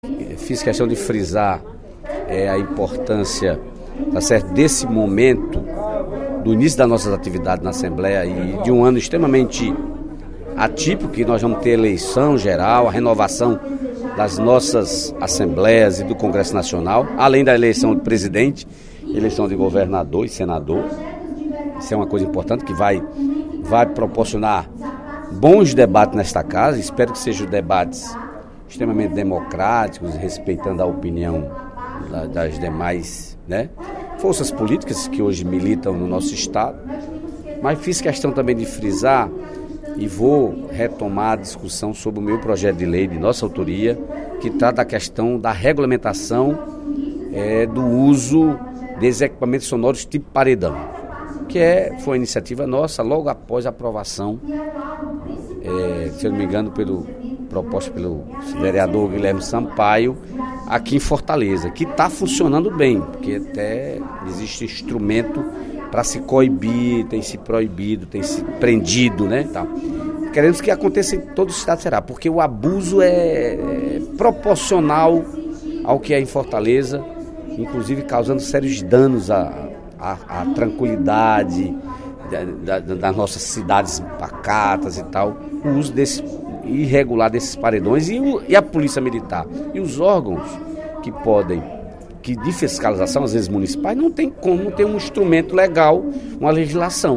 Durante o primeiro expediente da sessão plenária desta terça-feira (04/02), o deputado Dedé Teixeira (PT) disse que eventos importantes tornam 2014 atípico para os brasileiros, justamente por ser ano de eleição e também de Copa do Mundo.